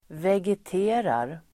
Ladda ner uttalet
vegetera verb (bildligt " leva i overksamhet"), vegetate [figuratively " lead a dull life"] Grammatikkommentar: x/A & Uttal: [veget'e:rar] Böjningar: vegeterade, vegeterat, vegetera, vegeterar Definition: leva (om växt)